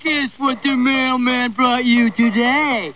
Guess What The Mailman Brought You Today! My sound I use in my email program to tell me I've got new mail.
newmail.wav